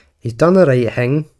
glaswegian_audio
glaswegian
scottish